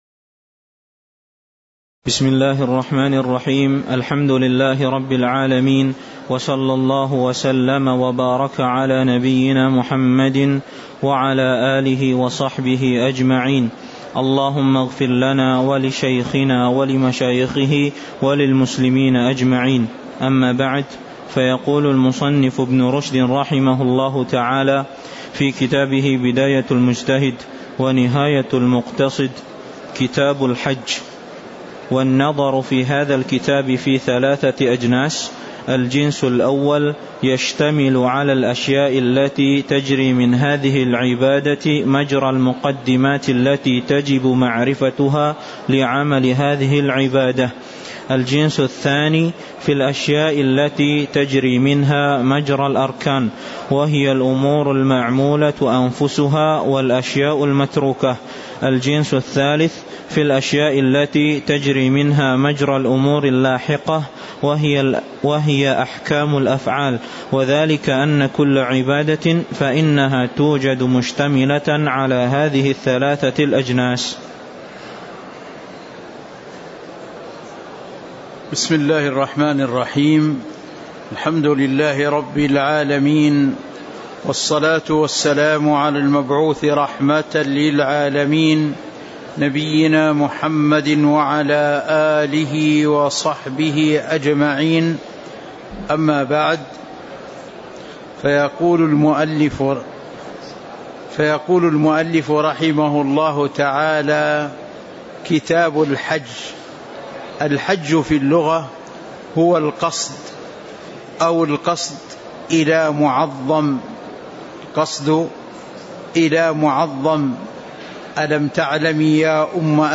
تاريخ النشر ١٥ ذو القعدة ١٤٤٤ هـ المكان: المسجد النبوي الشيخ